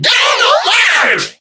mech_mike_kill_vo_02.ogg